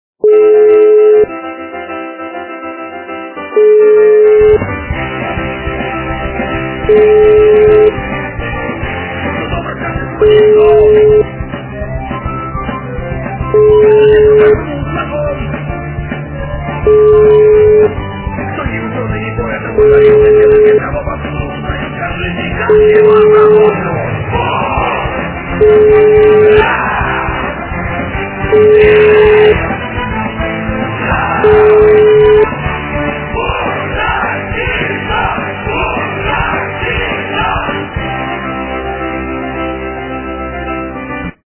При заказе вы получаете реалтон без искажений.